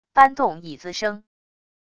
搬动椅子声wav音频